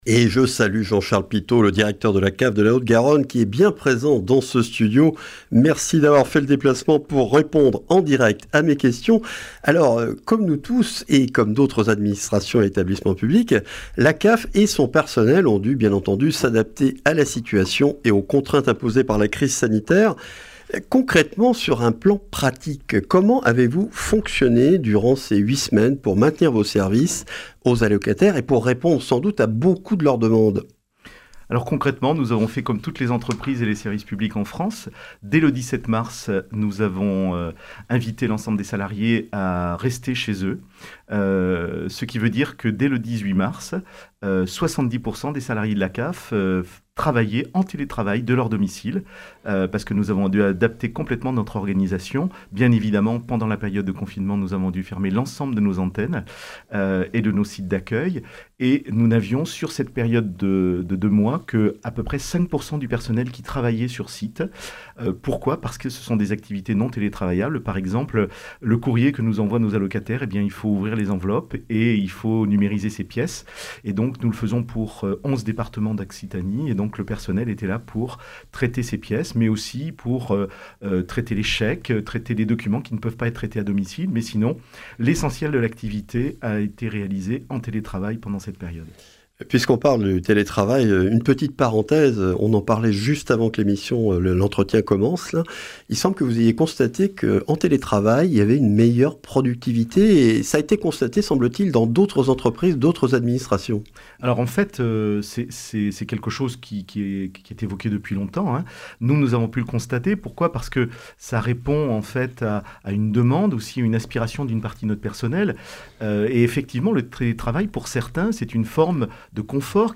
Accueil \ Emissions \ Information \ Régionale \ Le grand entretien \ Crise sanitaire : la CAF soutient ses allocataires et renouvelle ses services !